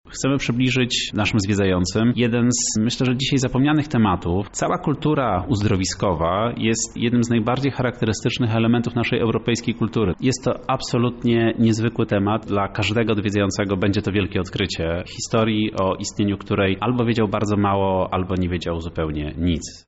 O wydarzeniu rozmawialiśmy z doktorem